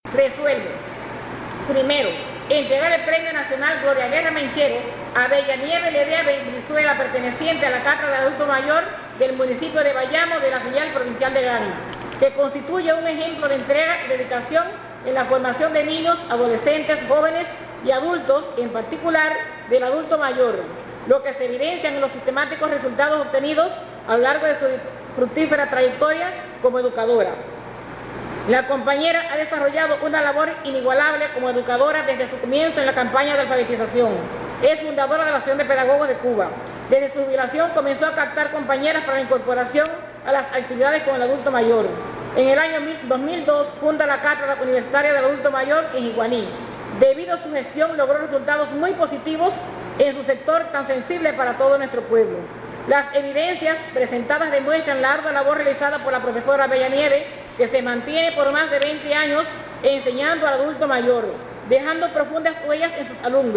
En acto realizado en el Salón de Protocolo de la Plaza de la Patria, en Bayamo